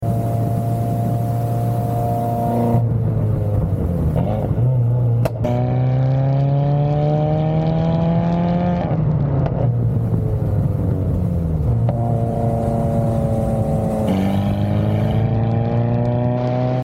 On track, our WRX is working overtime to close the gap on a BMW ahead. Turn up the ASMR for that turbo spool.